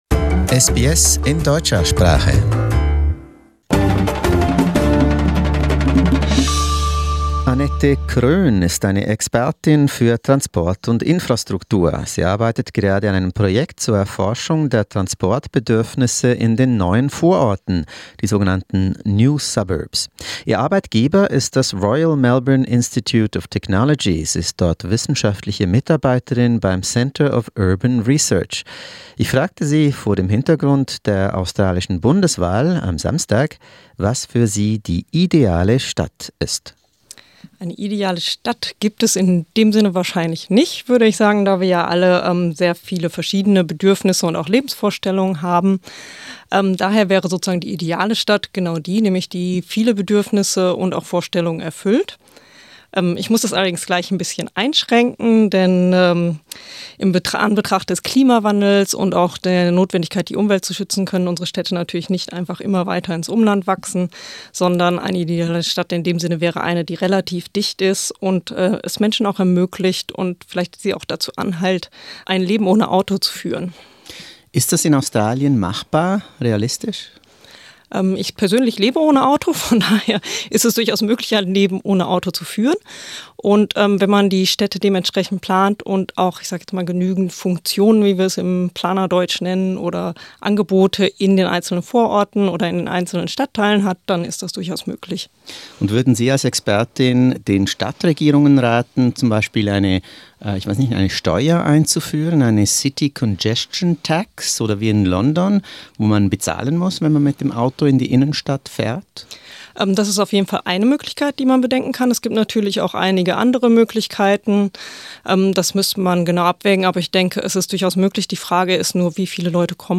Sollte Australien eine neue Stadt bauen, um die aus den Nähten platzenden Metropolen Melbourne, Sydney, Brisbane und Perth zu entlasten? Dazu ein Interview